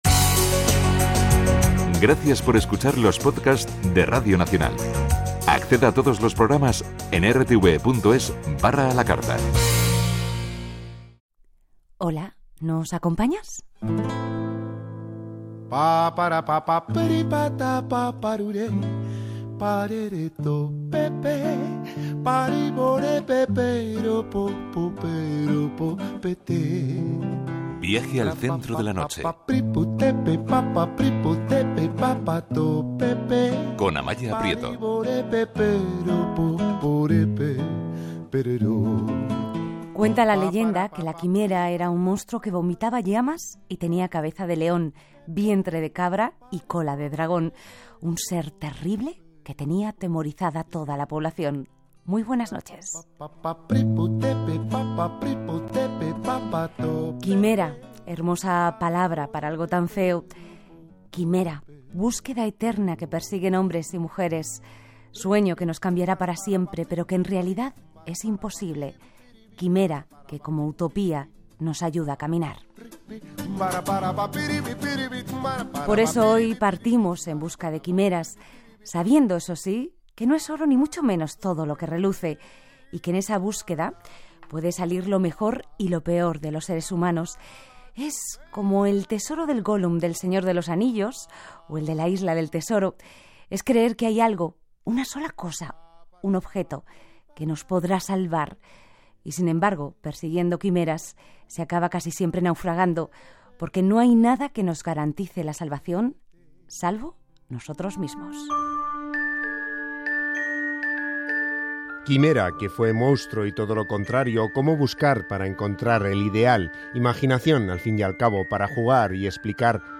Entrevista en Radio Nacional de España